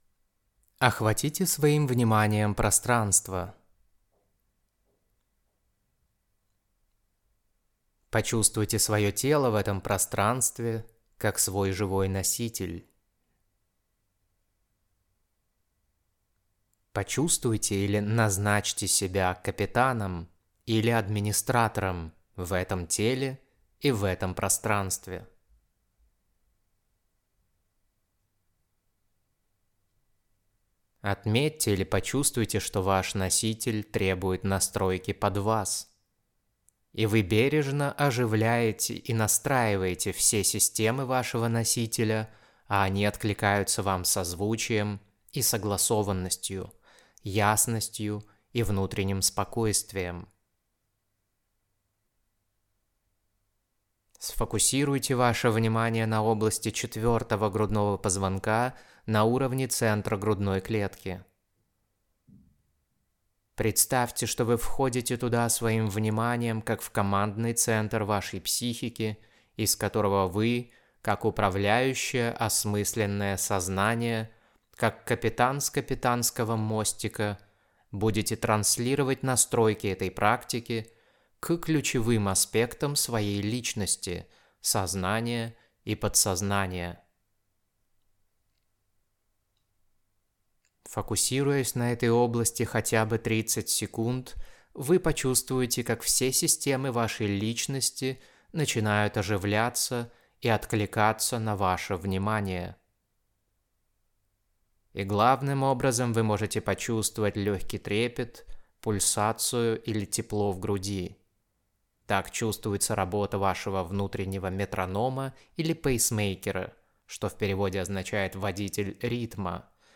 Практику не заучивайте, а делайте по текстовой версии через методичку, либо под аудио версию с моей озвучкой.
Раскрытие-Многомерного-Я.-Практика.mp3